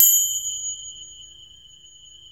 percussion 14.wav